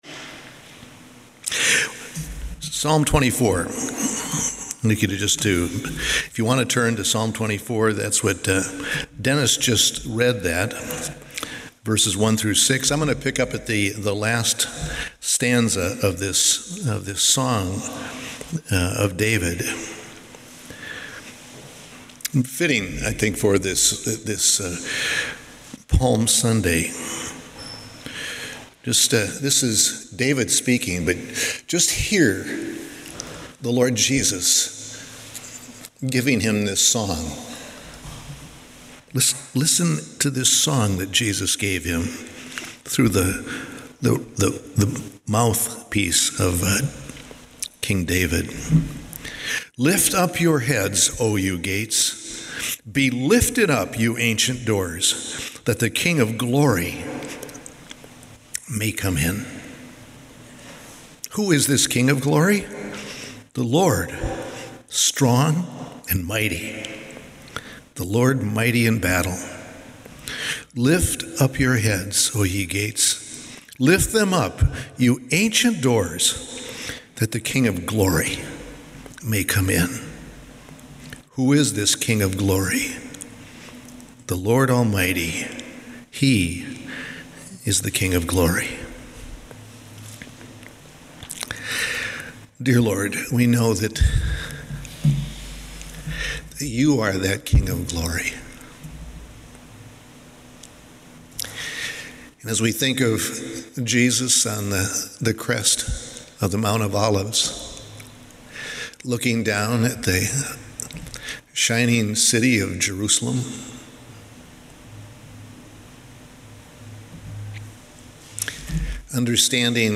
Praise Symphony Orchestra performs at Calvary | El Calvario Ministries
2022-Palm-Sunday.mp3